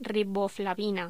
Locución: Riboflavina
voz
Sonidos: Voz humana